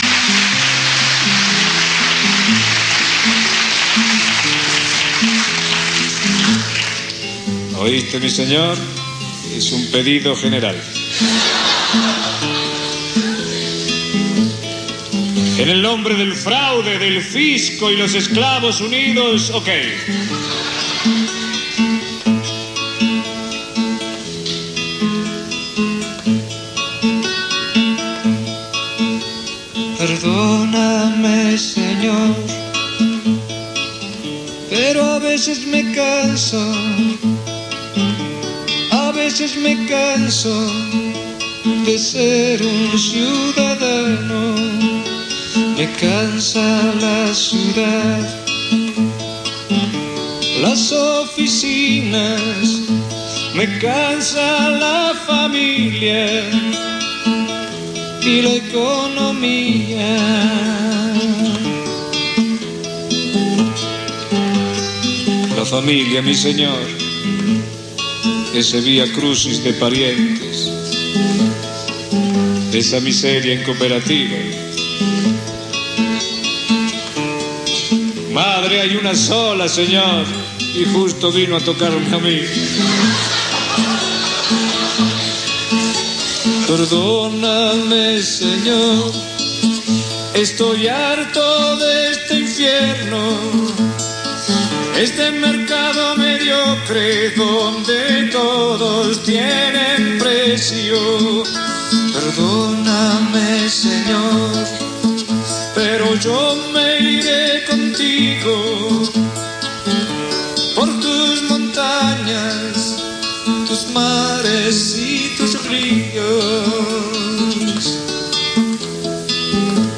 De él leemos el relato «Fitá», sobre los modos abominables de acometer el poder en las sociedades y la vida misma en esas sociedades.